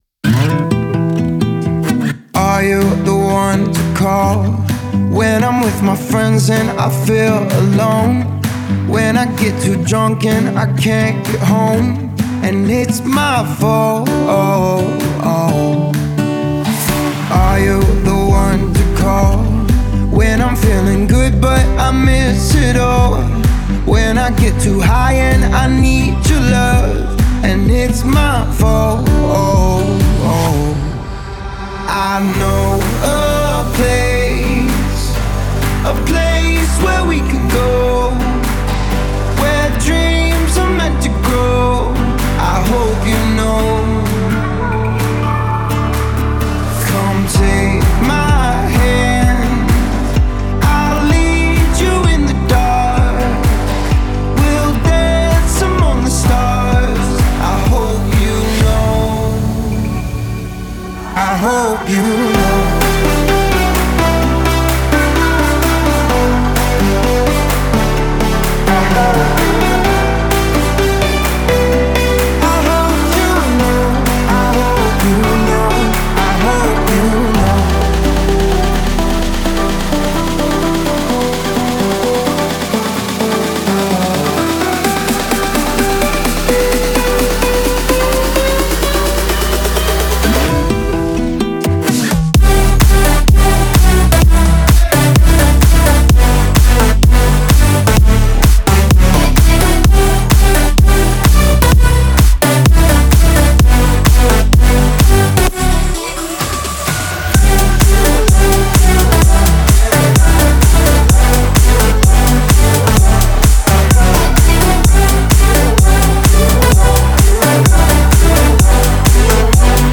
энергичная электронная танцевальная композиция